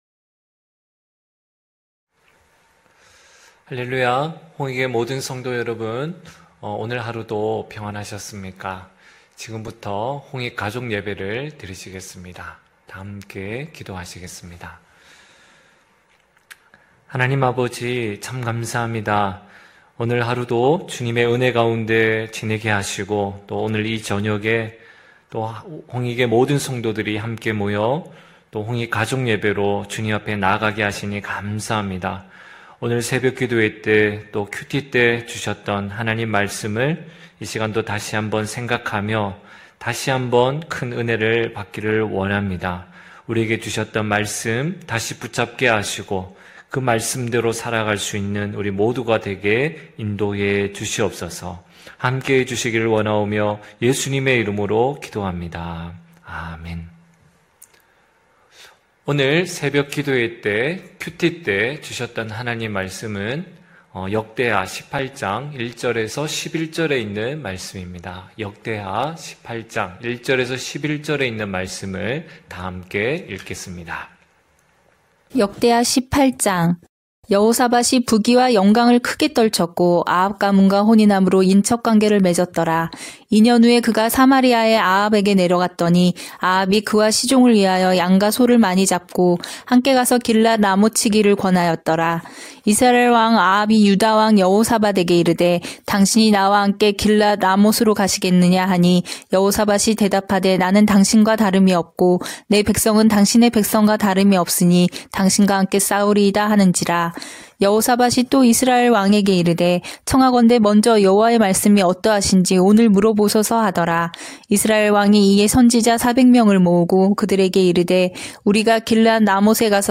9시홍익가족예배(11월21일).mp3